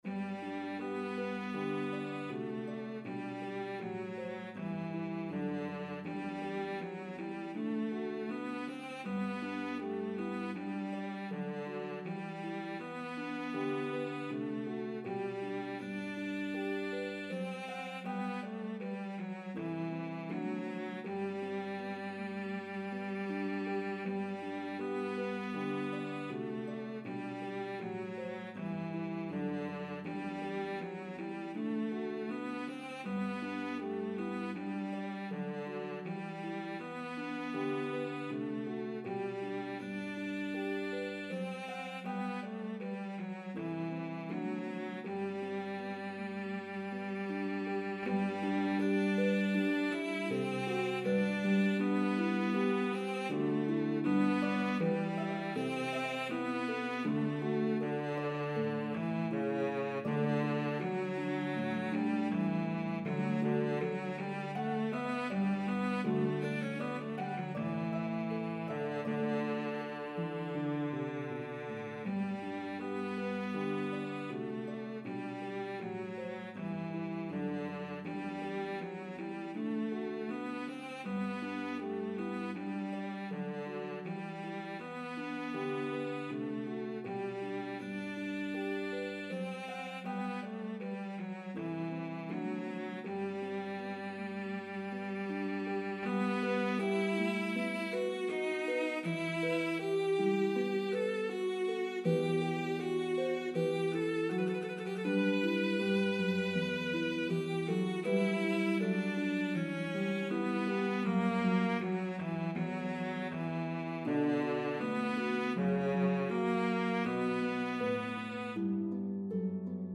This well known Baroque piece